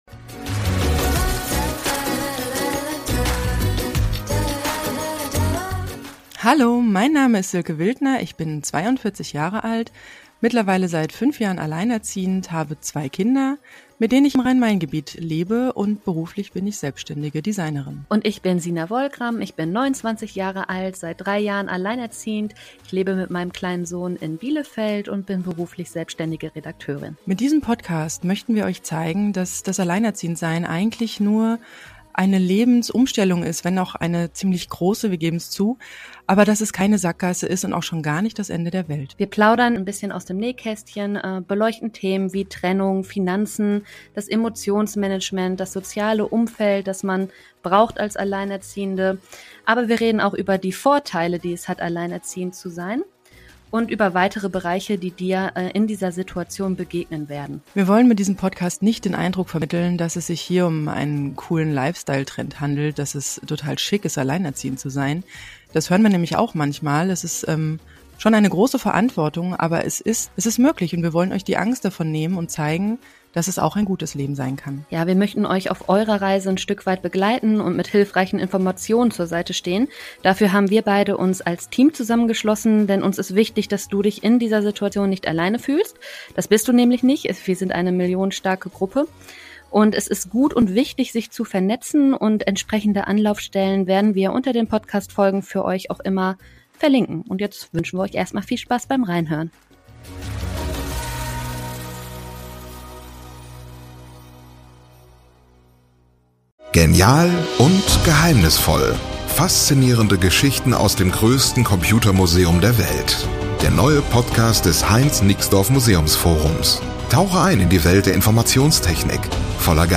Wir sind zwei alleinerziehende Mütter, die beide ungeplant in die Situation gerutscht sind, als Single Moms ihren Alltag rocken zu müssen. Es geht um Schwierigkeiten, besondere Herausforderungen, Rechte und Pflichten, vor allem aber um viel positiven Input, emotionale Unterstützung und Optimierung des Mindsets von Alleinerziehenden für Alleinerziehende.